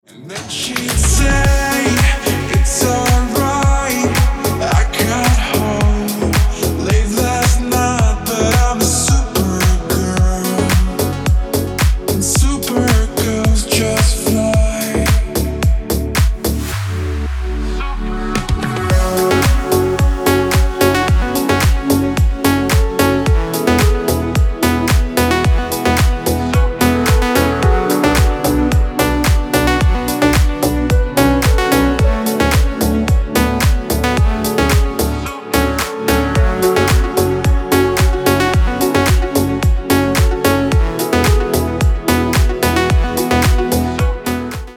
Deep House рингтоны